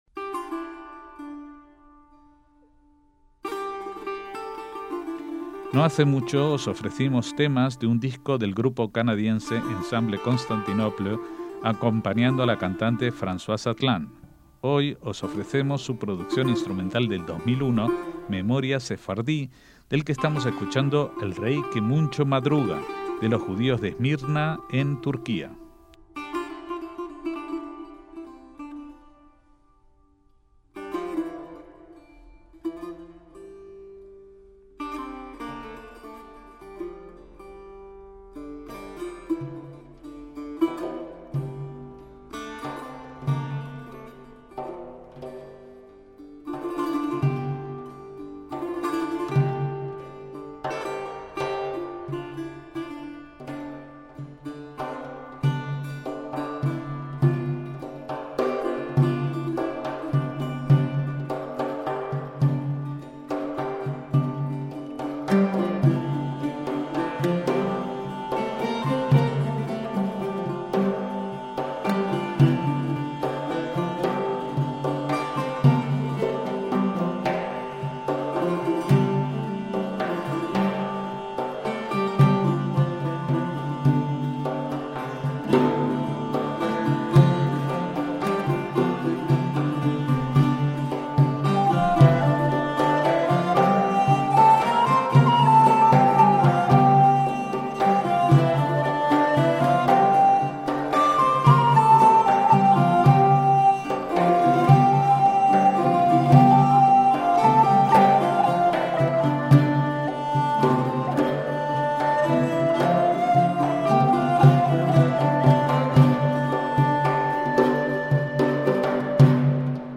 MÚSICA SEFARDÍ
es un grupo de música medieval y de Medio Oriente
cordófono setar
percusión tumbak
viola da gamba, cornetas medievales y pandero daf